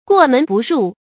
注音：ㄍㄨㄛˋ ㄇㄣˊ ㄅㄨˋ ㄖㄨˋ
過門不入的讀法